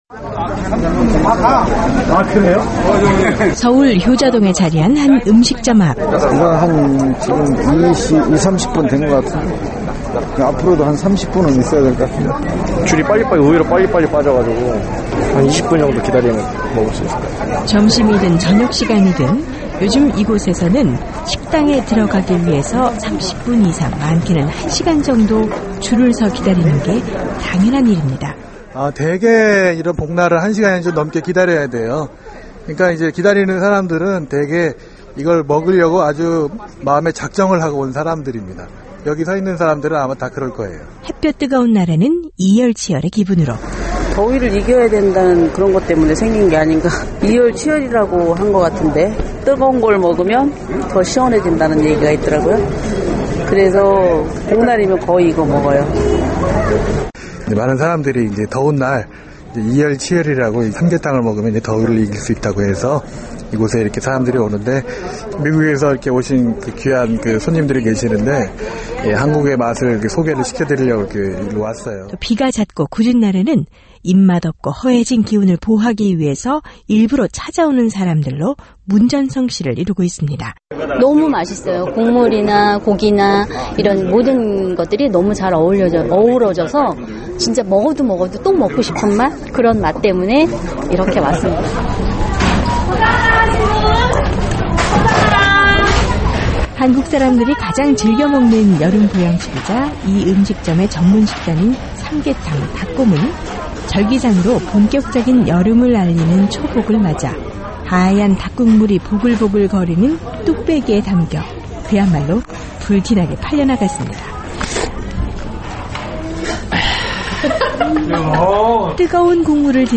복날 보양식을 즐기는 한국사람들의 목소리를 담아봤습니다.